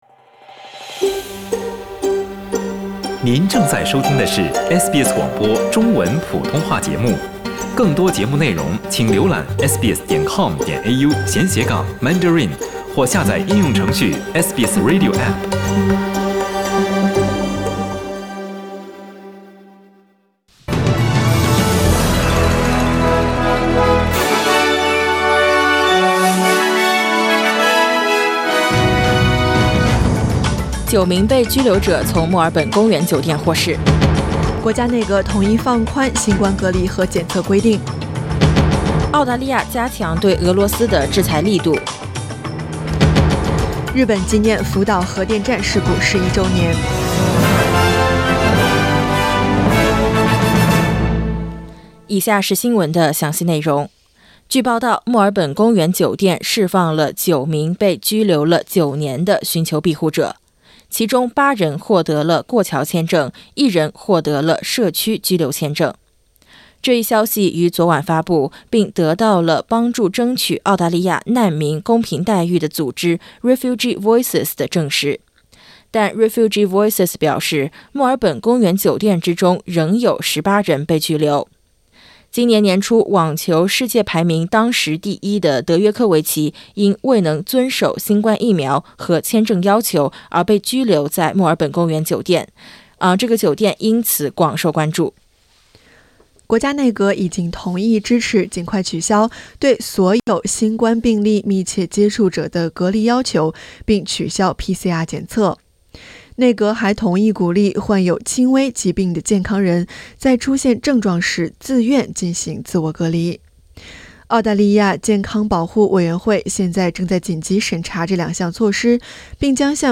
SBS早新闻（2022年3月12日）
SBS Mandarin morning news Source: Getty Images